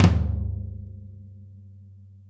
Tom Shard 04.wav